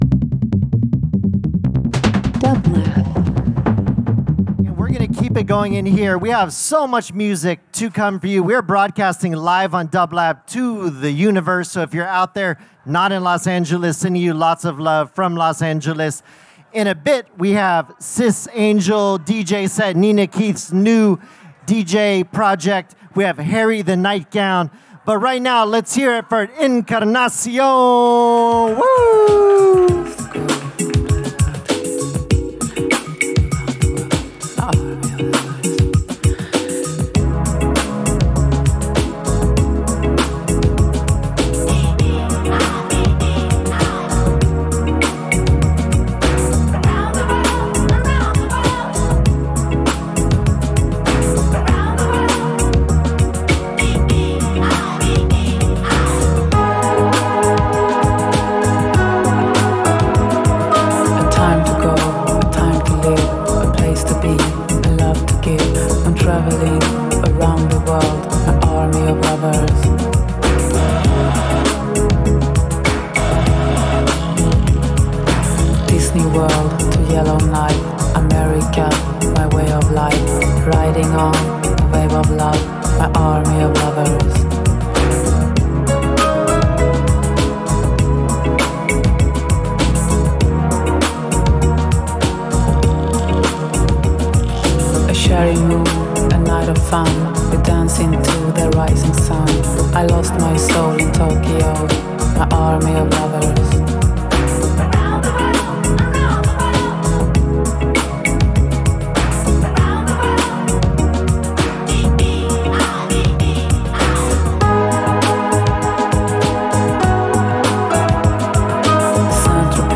[LIVE FROM THE LOFT @ PORTER STREET STUDIO – OCT 11, 2025]
Alternative Disco Electronic Funk/Soul New Wave